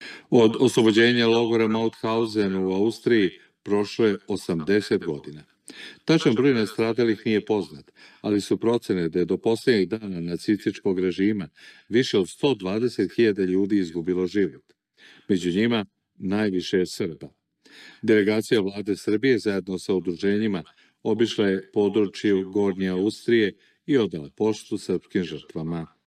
Prvi program Radio Beograda je 11. maja emitovao vest o osamdesetoj godišnjici od oslobođenja nacističkog logora Mauthauzen i tom prilikom plasirao neistinu o nacionalnoj strukturi žrtava ovog logora. „Od oslobođenja logora Mauthauzen u Austriji, prošlo je 80 godina. Tačan broj nastradalih nije poznat, ali su procene da je do poslednjeg dana nacističkog režima više od 120.000 ljudi izgubilo život. Među njima, najviše je Srba“, čulo se u popodnevnim vestima na prvom radijskom programu javnog servisa.